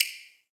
menuclick.ogg